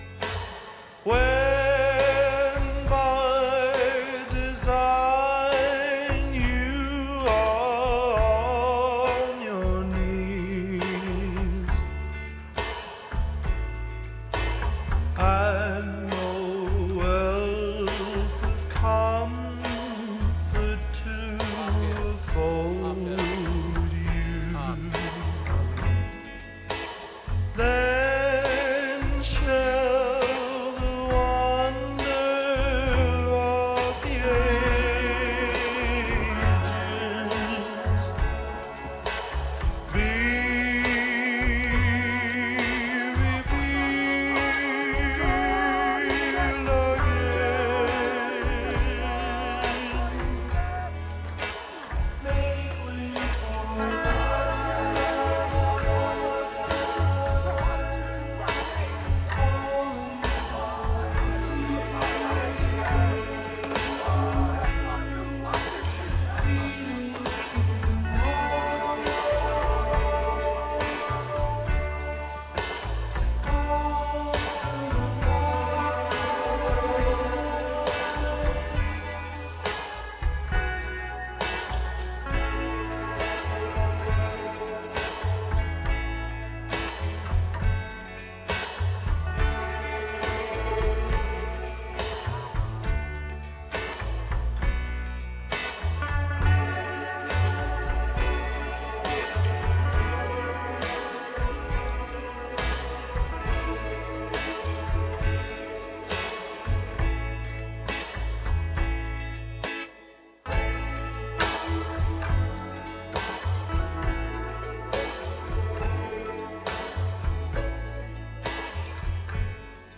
MP3 clip from this title's soundtrack